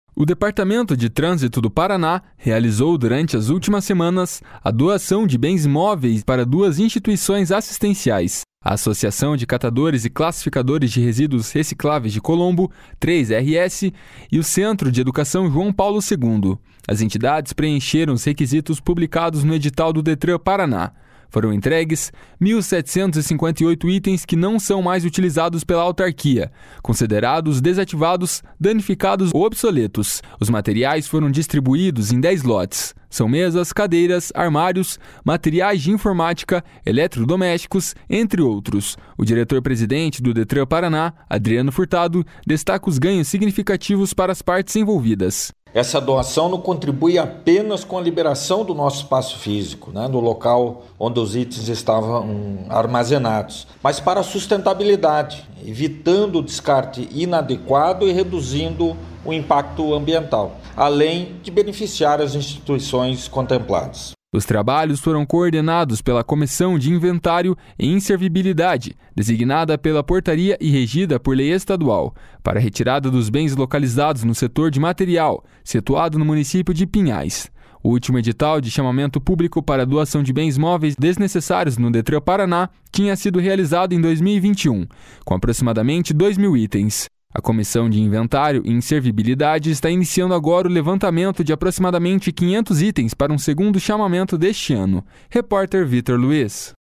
O diretor-presidente do Detran-PR, Adriano Furtado, destaca os ganhos significativos para as partes envolvidas.// SONORA ADRIANO FURTADO.//